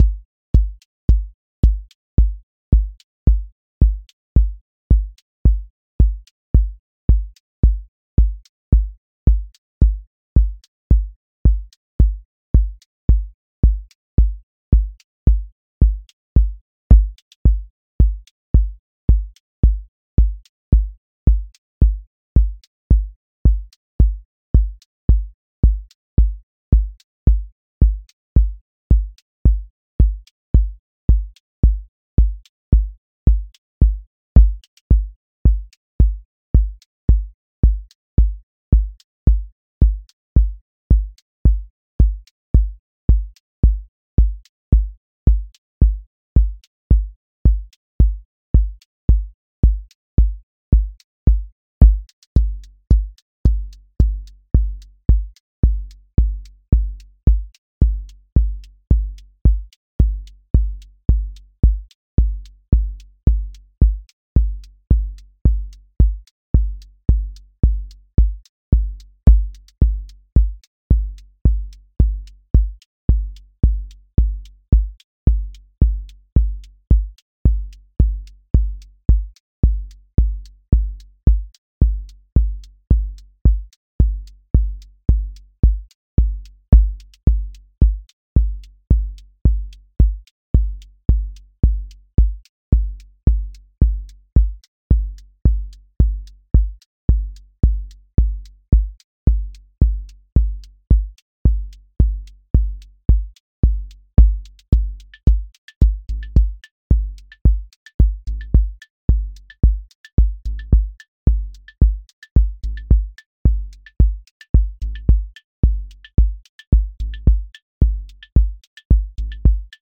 QA Listening Test house Template: four_on_floor
120-second house song with grounded sub, counter motion, a bridge lift, and a clear return
• macro_house_four_on_floor
• voice_kick_808
• voice_hat_rimshot
• voice_sub_pulse
• motion_drift_slow
• tone_warm_body